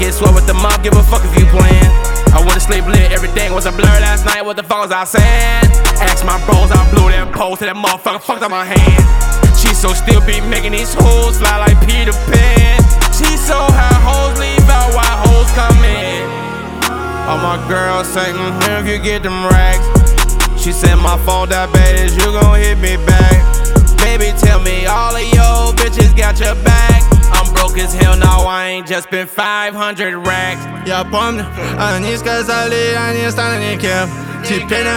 Жанр: Рэп и хип-хоп / Иностранный рэп и хип-хоп / Русские
# Hip-Hop